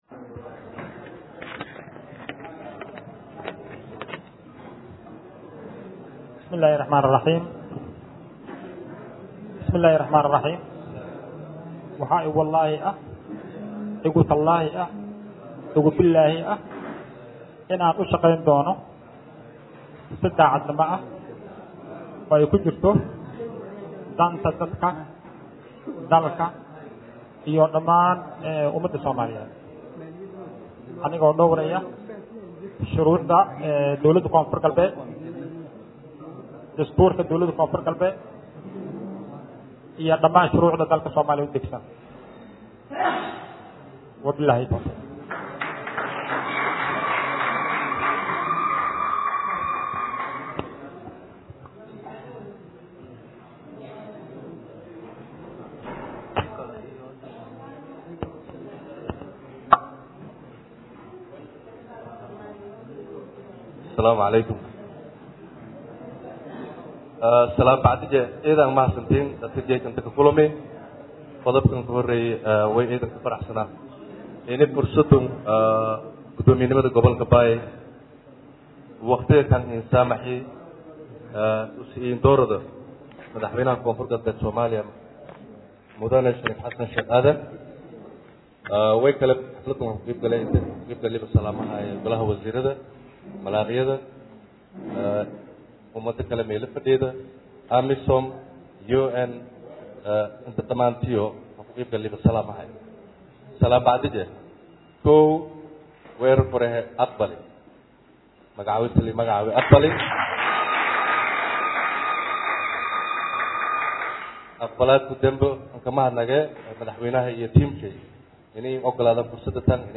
Xafladda Magacaabidda mas’uuliyiintan ayaa waxaa joogay ka ahaa Wasiirrada Dowladda Koonfur-Galbeed, nabaddoonnada, Saraakiisha AMISOM iyo Hay’adaha Samafalka.
Gudoomiyagha-Cusub-Ee-Gobolka-Baay-Abdirashiid-Cabdulaahi.mp3